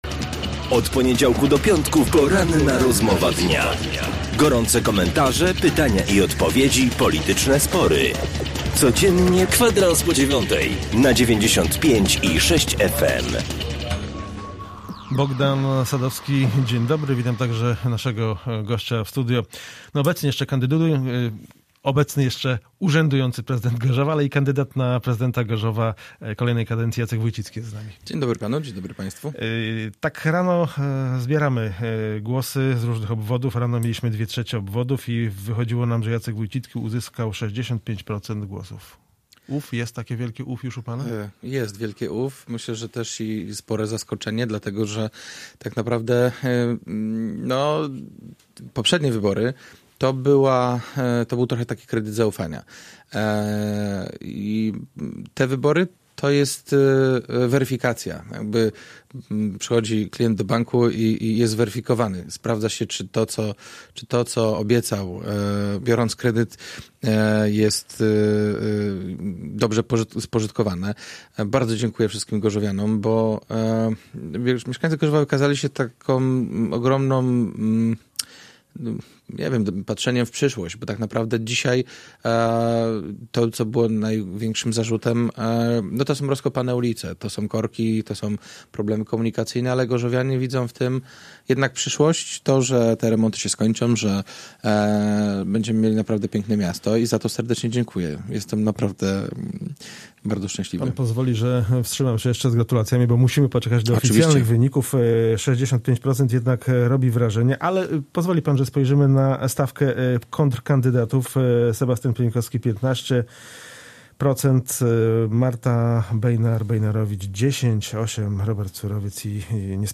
Z prezydentem Gorzowa rozmawiał